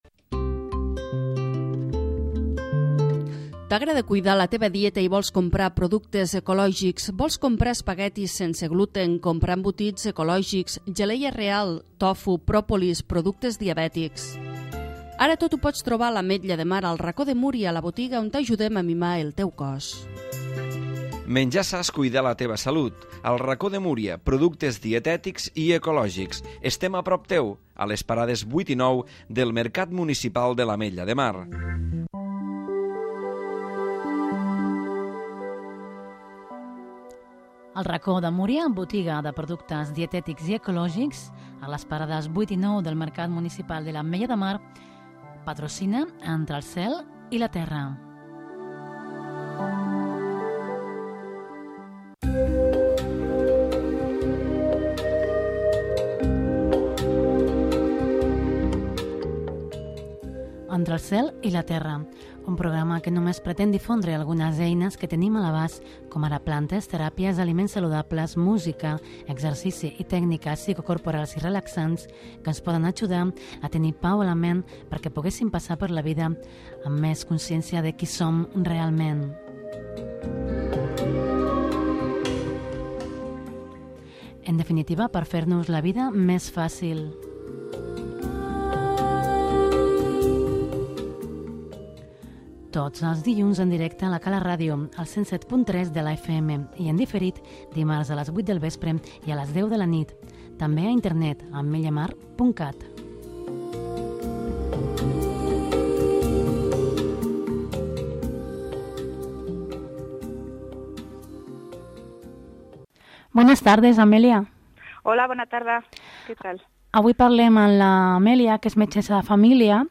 Per acabar, farem una reflexió sobre el cos encotillat. Tot, com sempre, amanit per música new age.